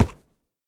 horse_wood6.ogg